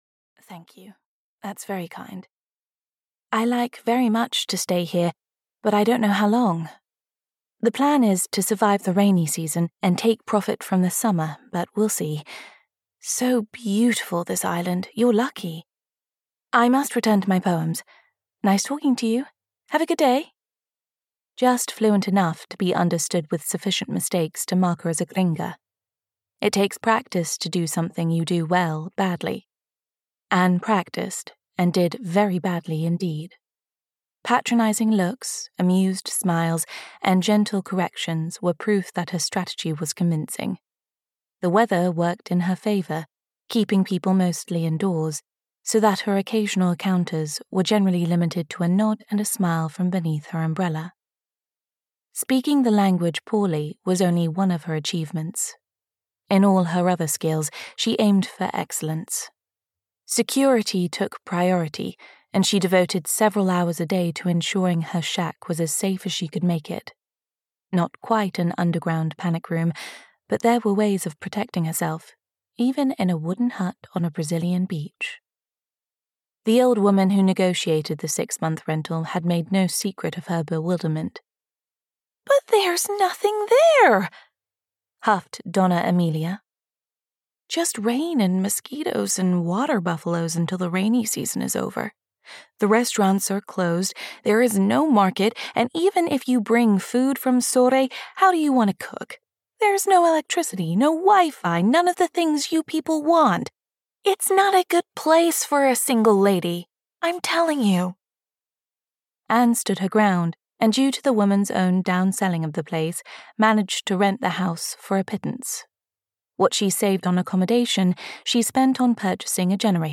White Heron (EN) audiokniha
Ukázka z knihy